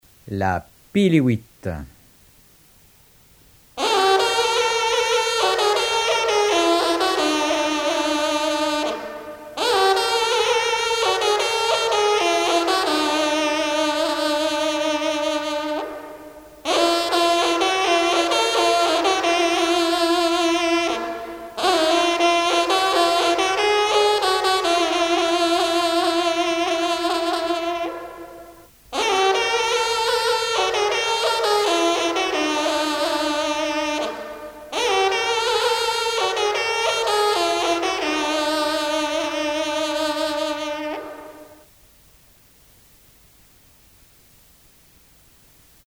trompe - fanfare - personnalités
circonstance : vénerie
Pièce musicale éditée